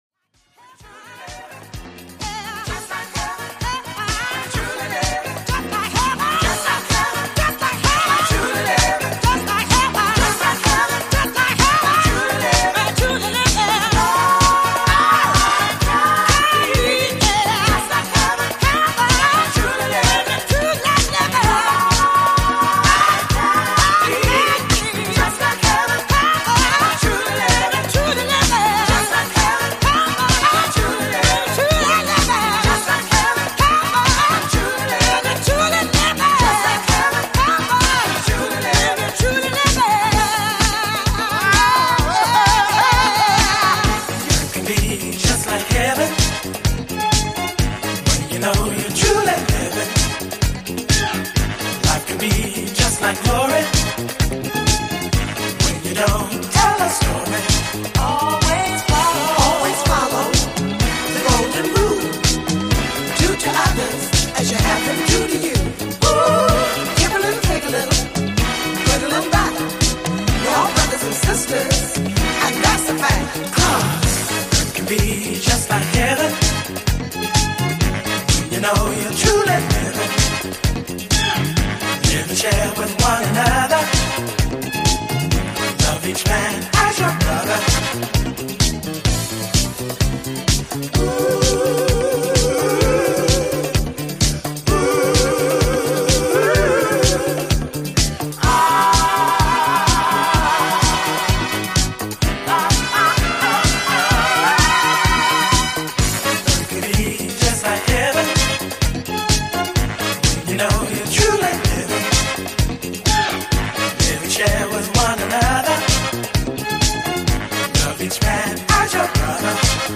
ジャンル(スタイル) DISCO